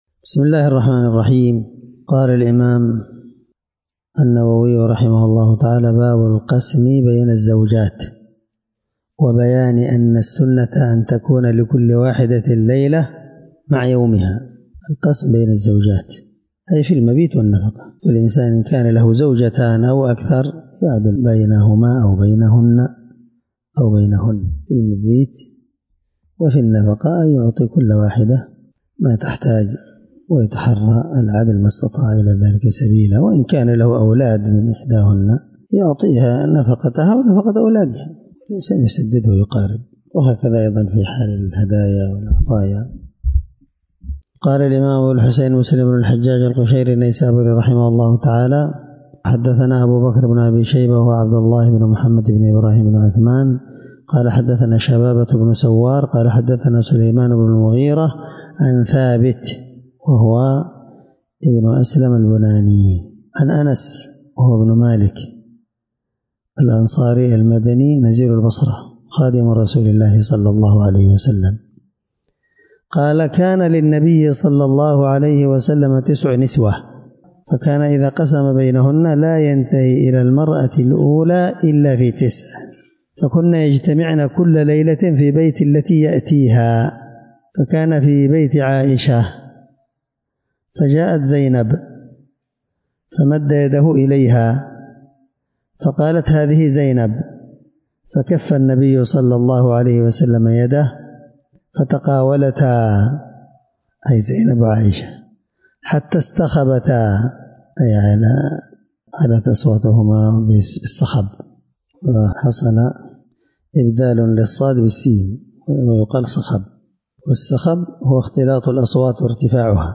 الدرس13 من شرح كتاب الرضاع حديث رقم(1462) من صحيح مسلم